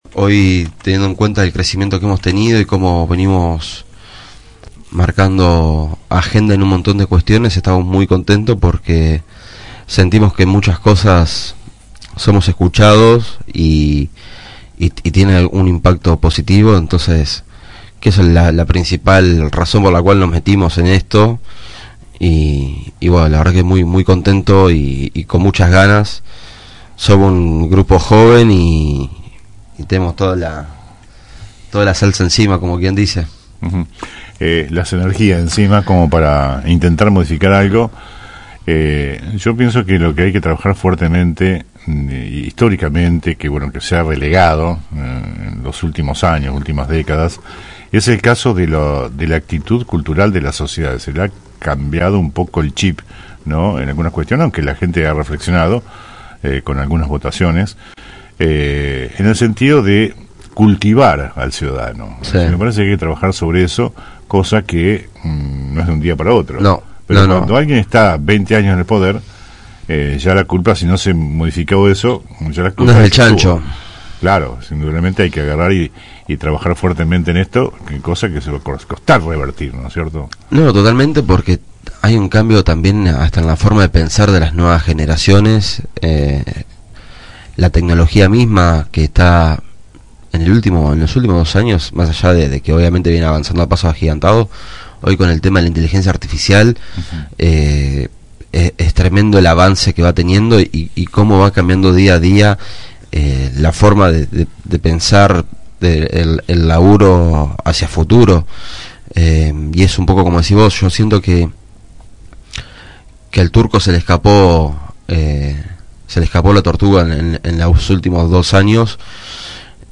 Conversamos en “EL Periodístico” con el concejal Bernardo Lemma. El flamante edil y principal referente de la LLA en nuestra ciudad, con pocos pelos en la lengua, se refirió a diversos temas, aunque antes de la consulta, se expresó al voto negativo de su bloque al convenio con el CEAMSE.